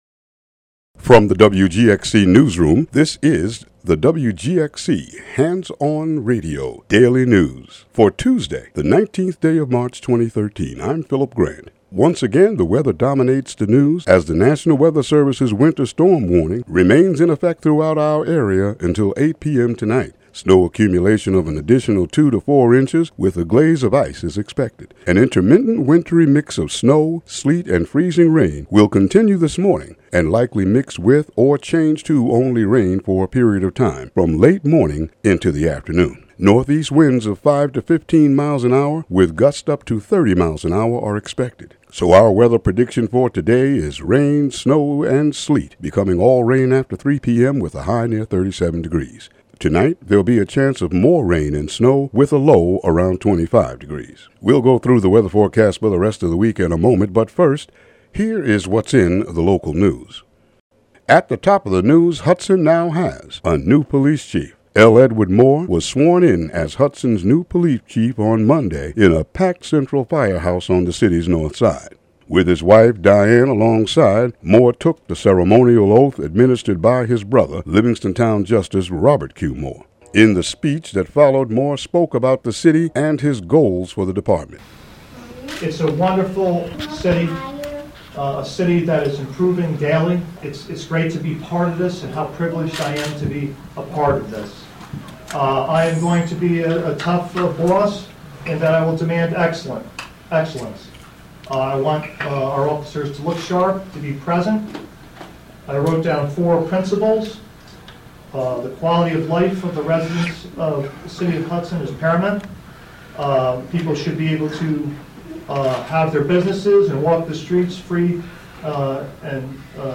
Local news headlines, weather and audio.